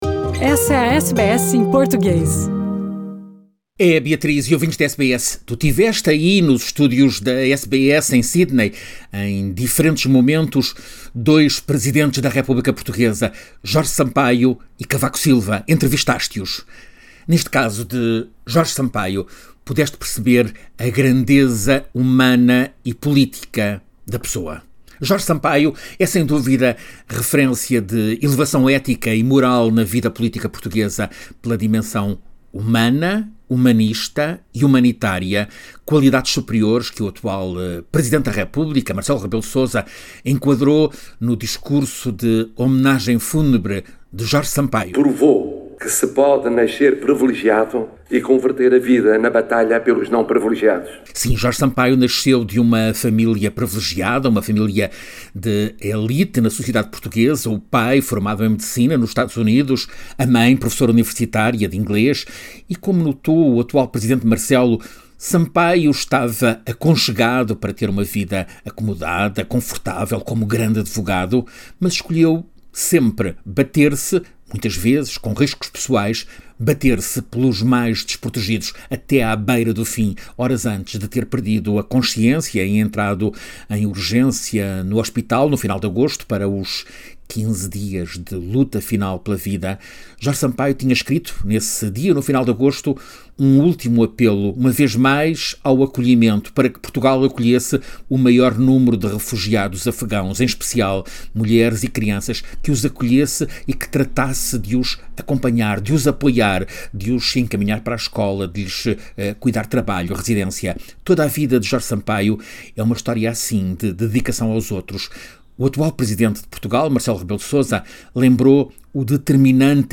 Jorge Sampaio foi muito mais do que o presidente de Portugal que mobilizou a ONU para a libertação de Timor-Leste, como nos conta direto de Lisboa